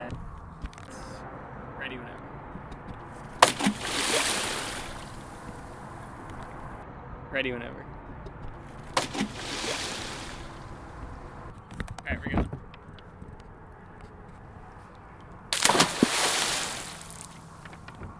big-splash-takes.mp3